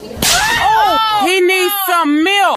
_Slap_x
slap-x.mp3